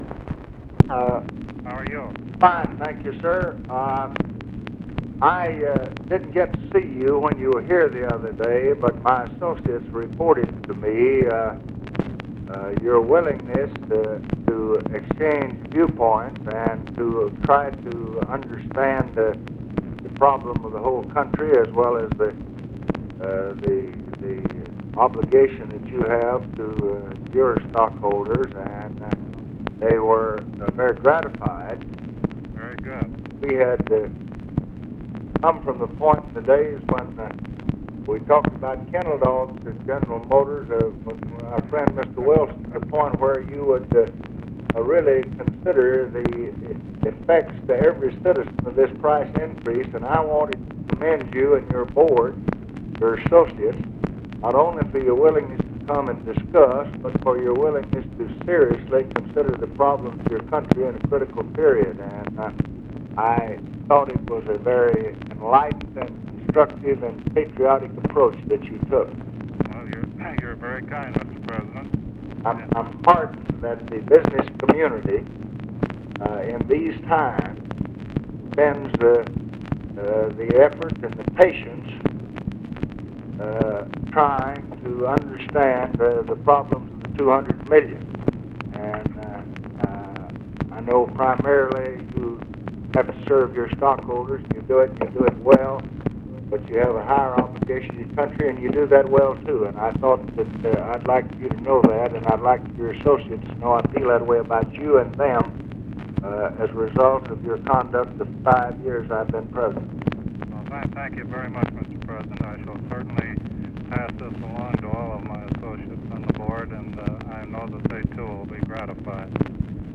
Conversation with JAMES ROCHE, September 26, 1968
Secret White House Tapes